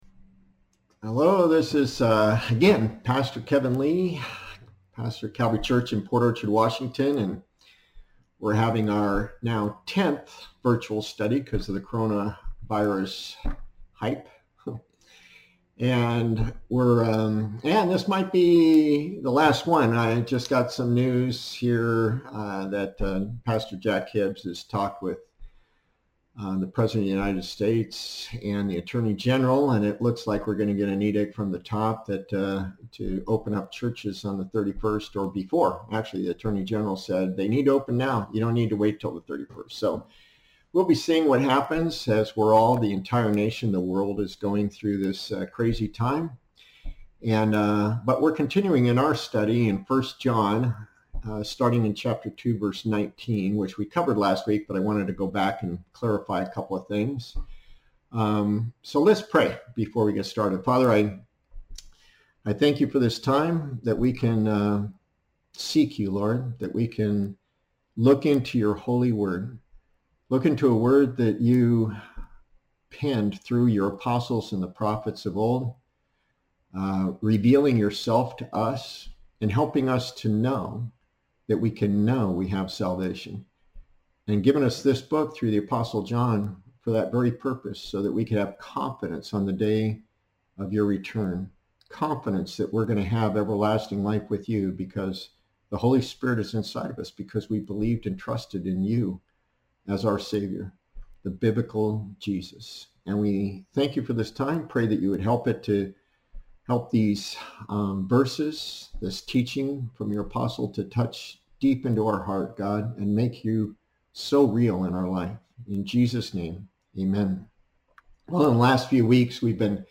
In this 10th Corona Virus lock-down virtual Bible study